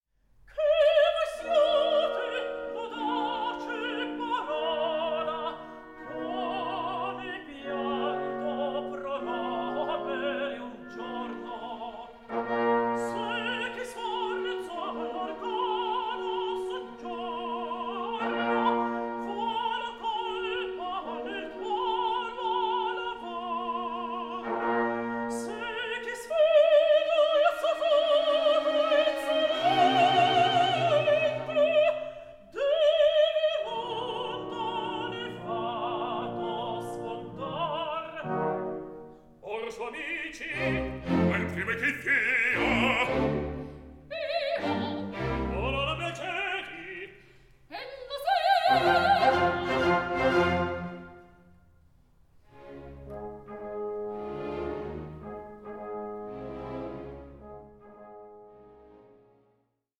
resulting in a performance that is lively and balanced.